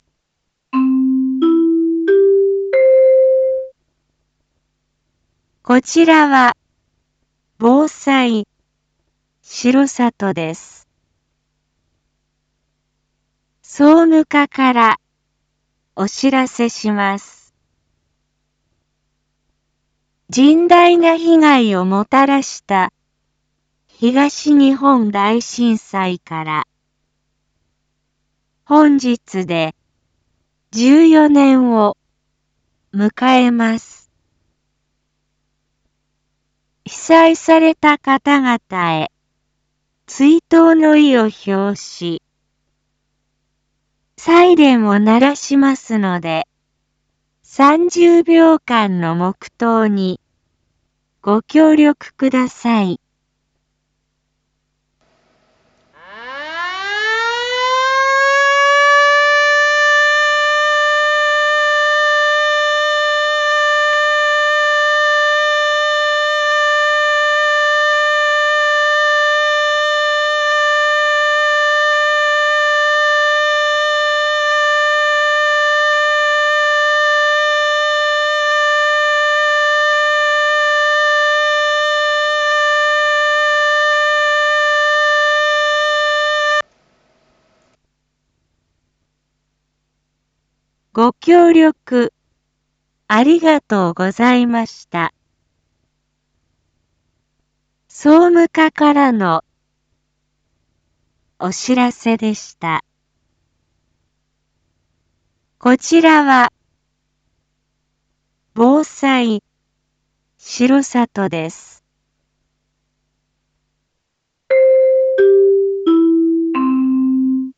BO-SAI navi Back Home 一般放送情報 音声放送 再生 一般放送情報 登録日時：2025-03-11 14:47:08 タイトル：東日本大震災追悼サイレン インフォメーション：こちらは、防災しろさとです。